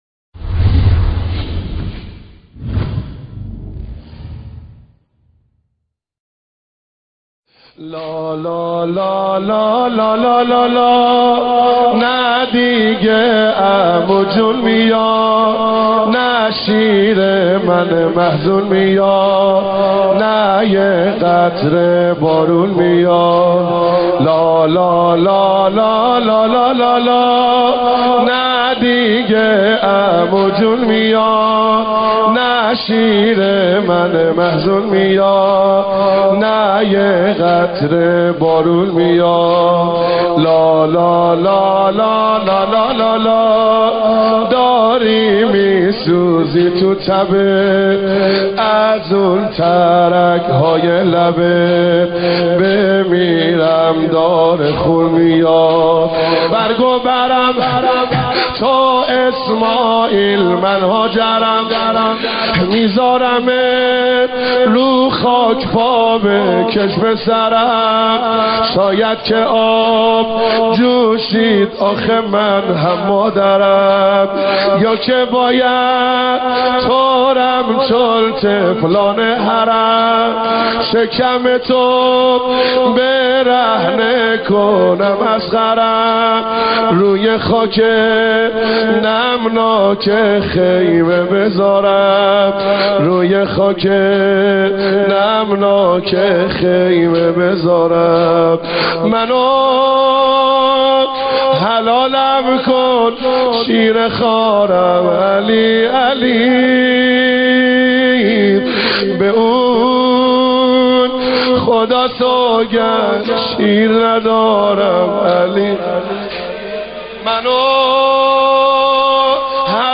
اشعار تک ضرب شب هفتم محرم